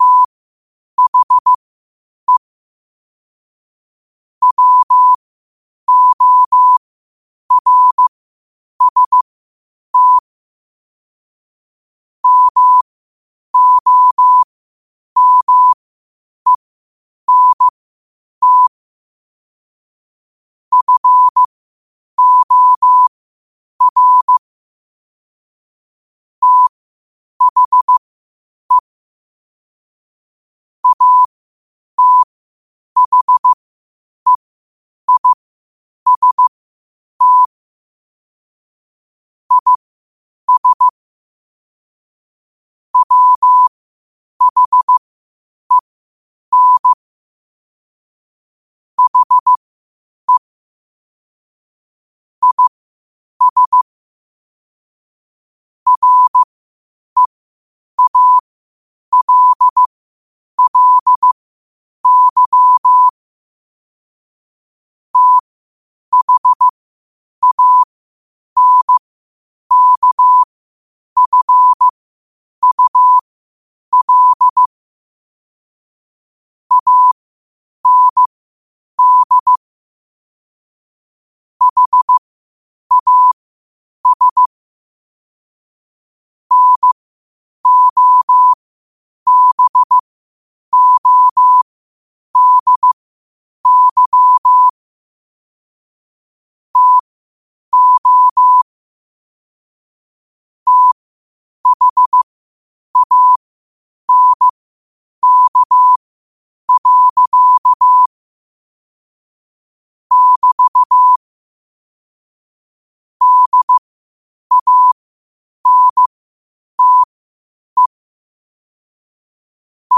Quotes for Fri, 15 Aug 2025 in Morse Code at 8 words per minute.